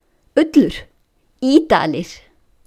Listen to pronunciation: Ullur Ýdalir